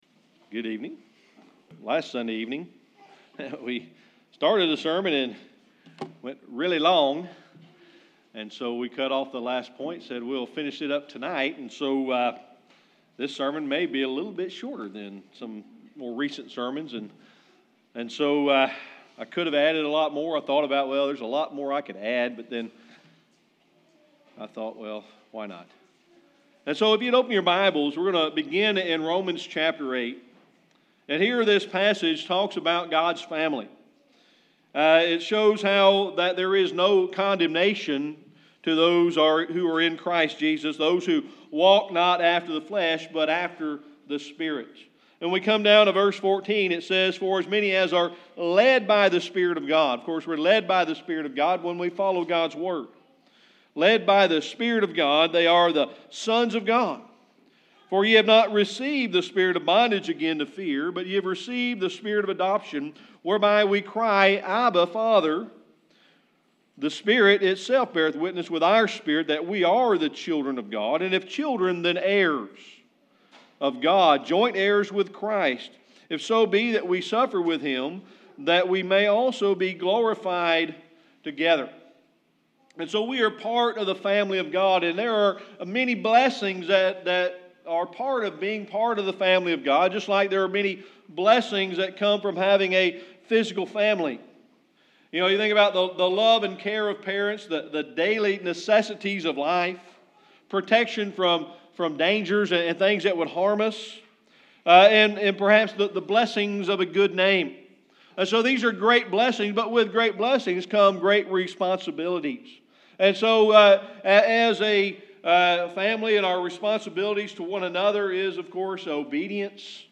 Series: Sermon Archives
Service Type: Sunday Evening Worship